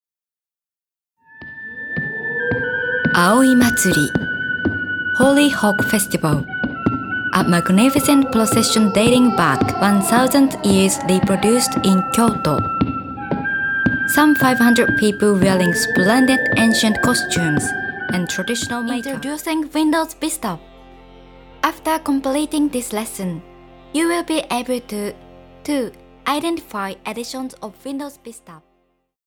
Clear, warm, sincere and friendly Japanese voice with 16 years experiences!
Sprechprobe: Sonstiges (Muttersprache):